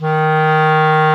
WND  CLAR 00.wav